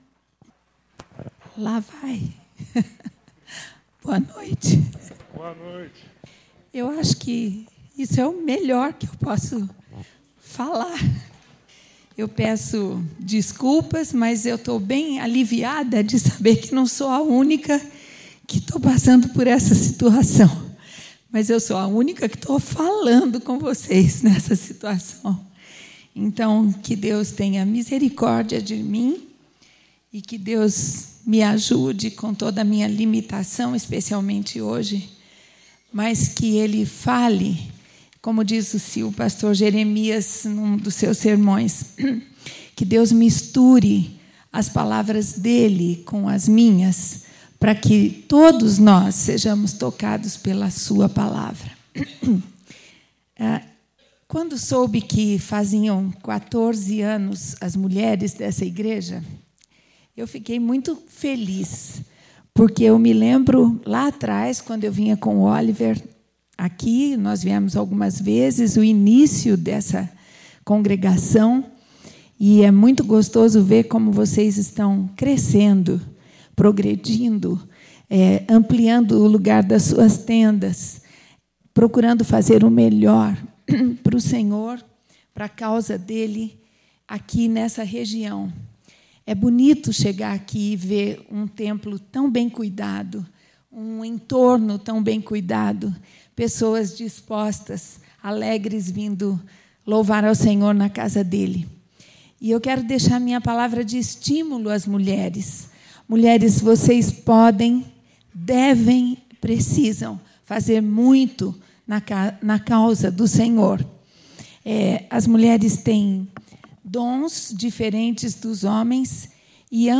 Mensagem Especial Culto de Aniversário de 14 Anos da MCM (Mulher Cristã em Missão)